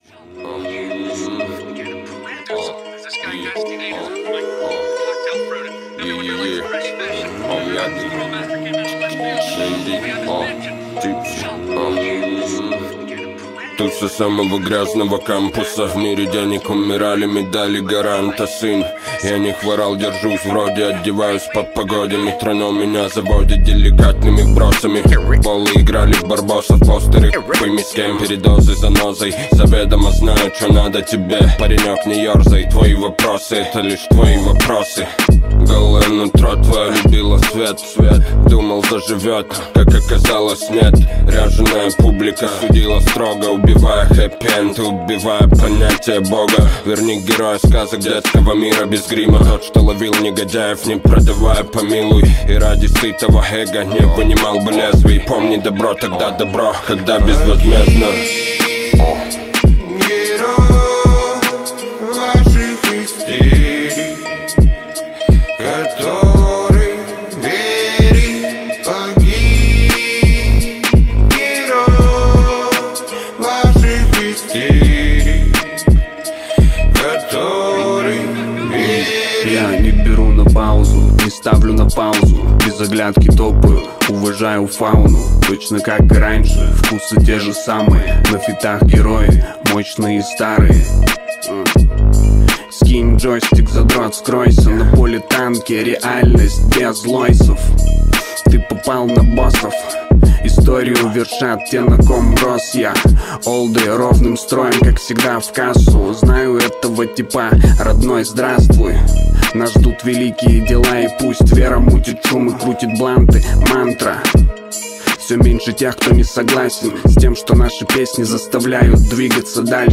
это мощный трек в жанре хип-хоп